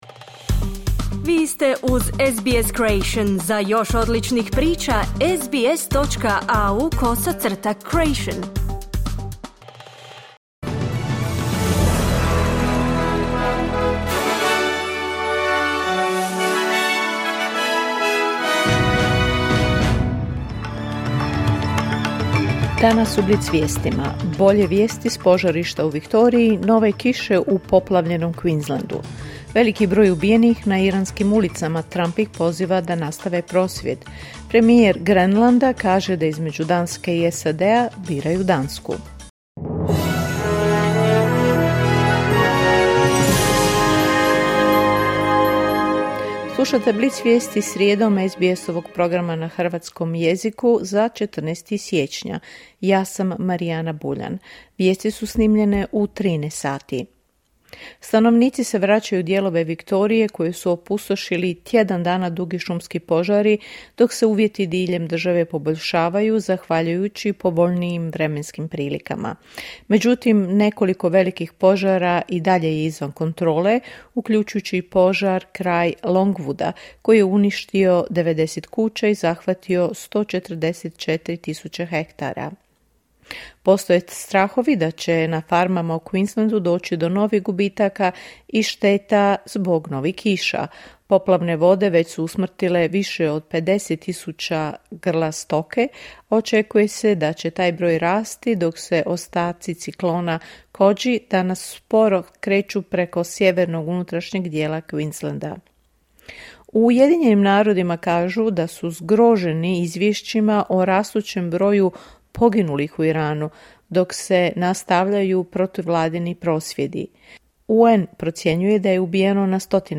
Vijesti radija SBS.